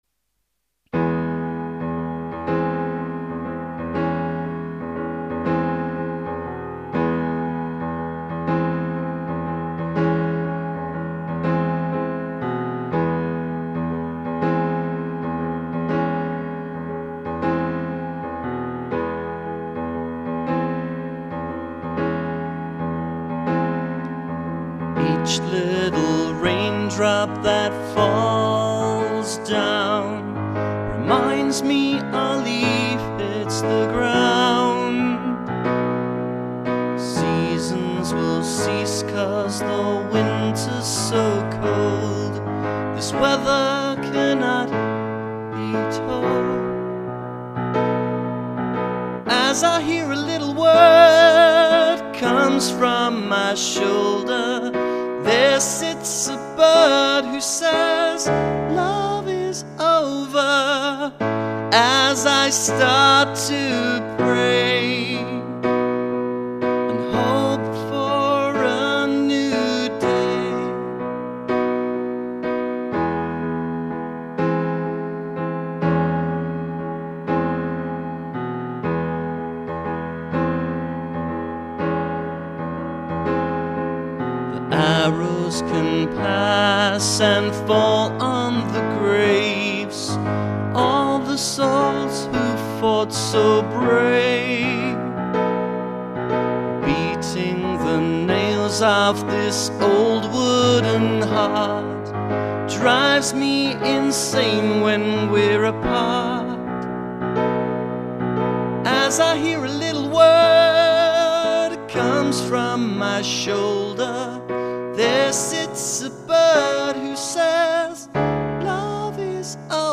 Singing track
lead vocals